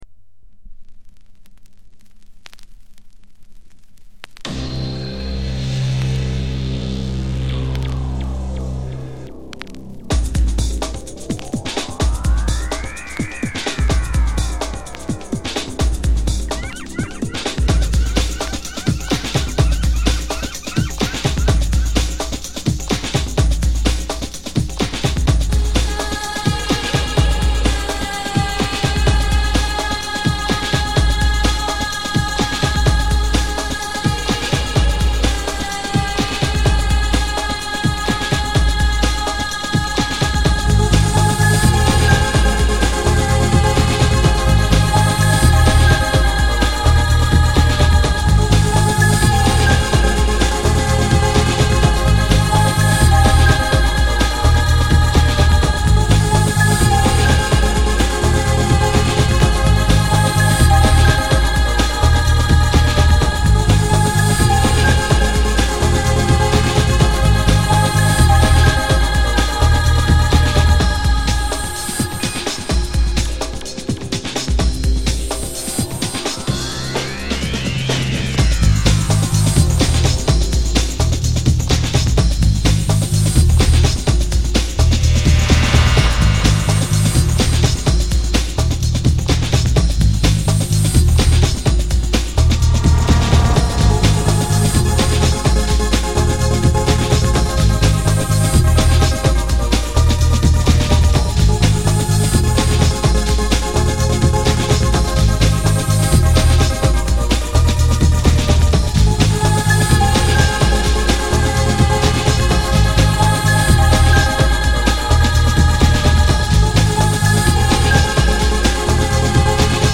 1 Hr Mix